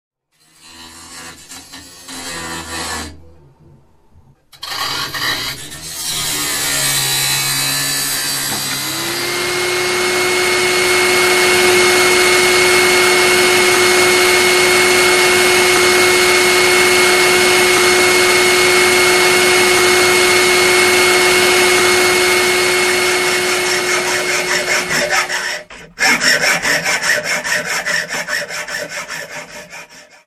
大きな修理の音 無料ダウンロードとオンライン視聴はvoicebot.suで